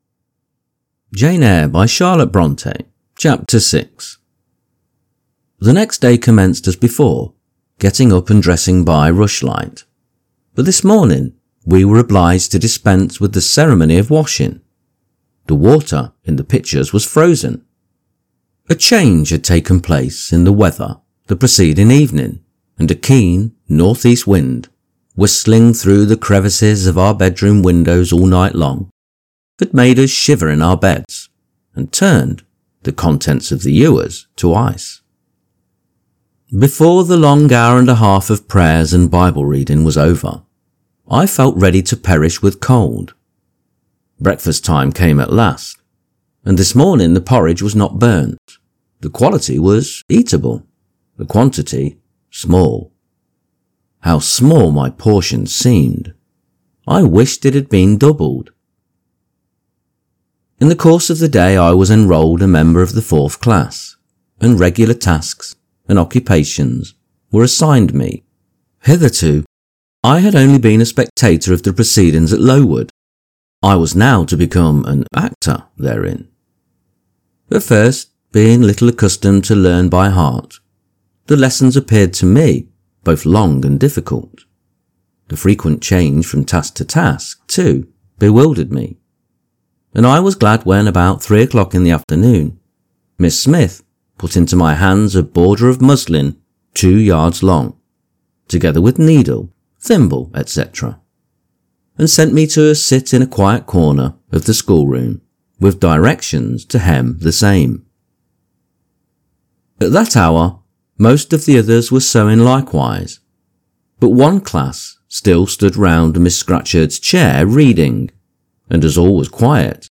Jane Eyre – Charlotte Bronte – Chapter 6 | Narrated in English - Dynamic Daydreaming